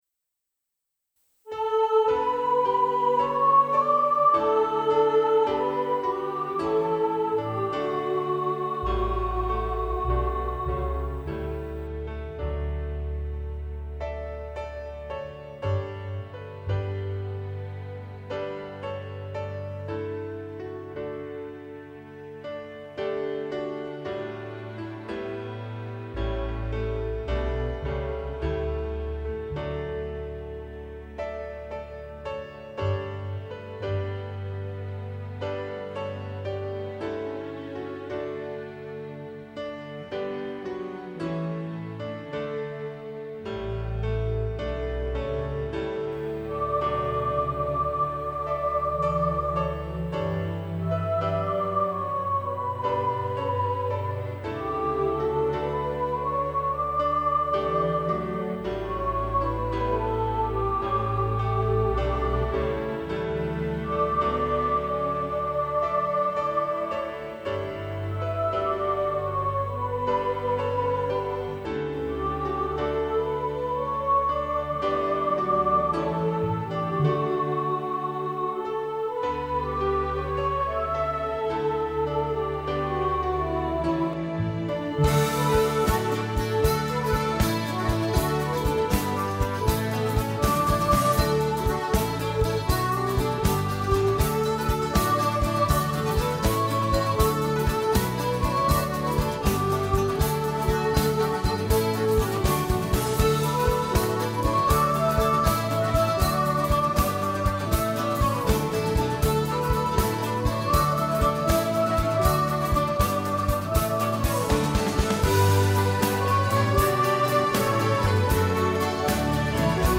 Fairytale Of New York Soprano 1 | Ipswich Hospital Community Choir